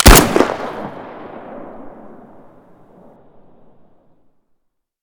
mp153_shoot_4.ogg